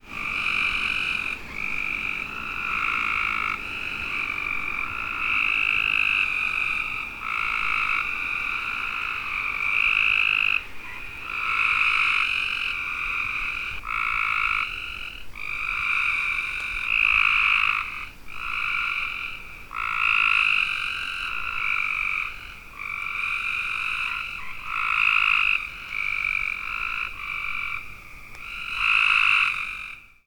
Sounds of Cope's Gray Treefrog - Hyla chrysoscelis
Advertisement Calls
Sound  This is a 30 second recording of the advertisement calls of a group of Cope's Gray Treefrogs made at night in late June in Sullivan County, Tennessee at the location shown to the right.